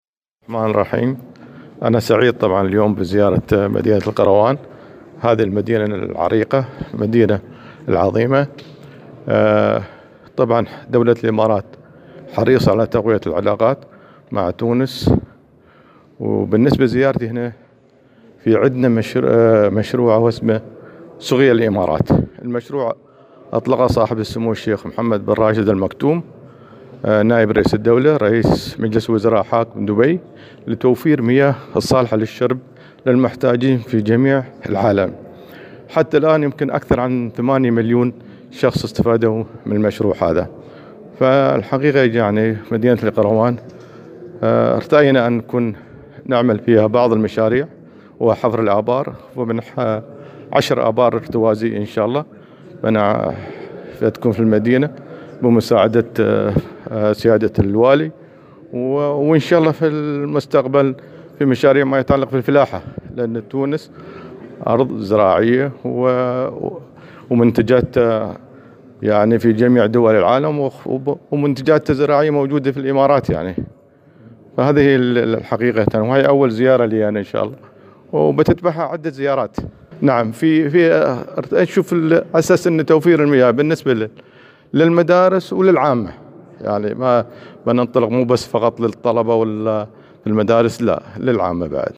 وعبّر السفير في تصريح لمراسل الجوهرة اف ام بالجهة، عن حرص بلاده على تقوية العلاقات مع تونس، مشيرا إلى أن التعاون بين البلدين قد يشمل المجال الفلاحي عبر انجاز مشاريع فلاحية في تونس.